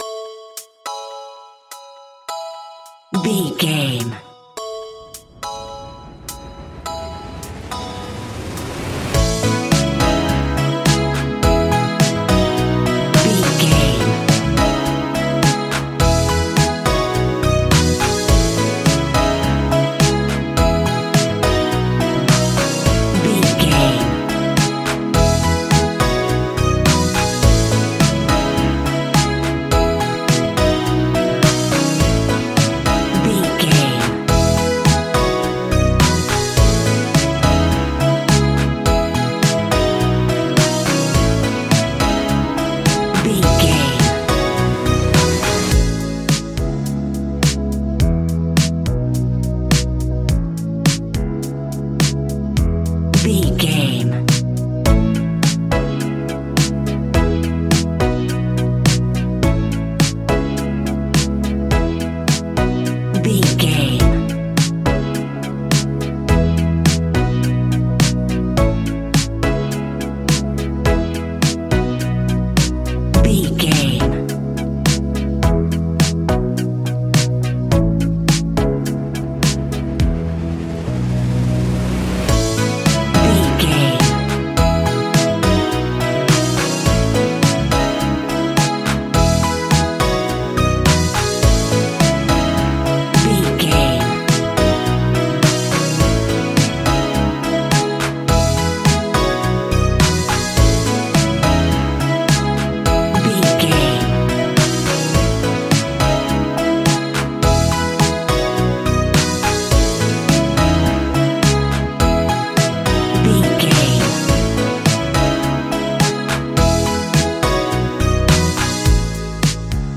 Ionian/Major
ambient
electronic
new age
chill out
downtempo
synth
pads